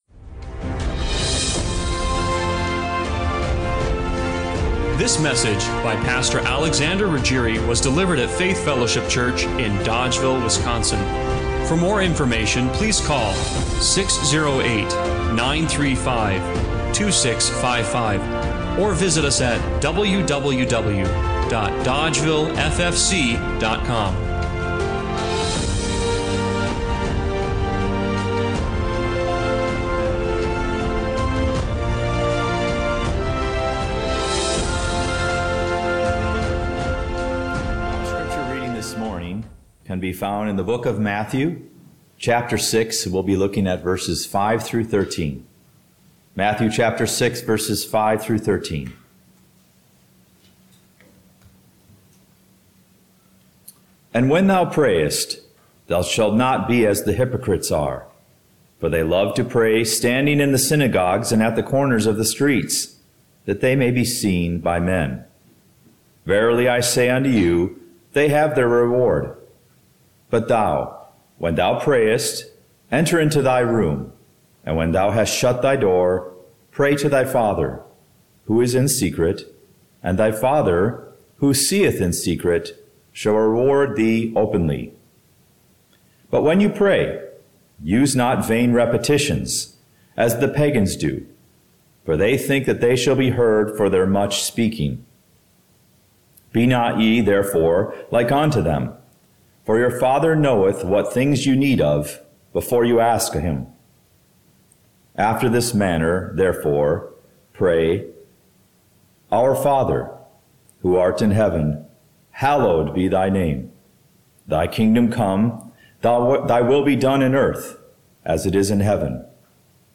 Matthew 6:5–13 Service Type: Sunday Morning Worship What if the Creator of the universe wasn’t just “a” Father—but your Father?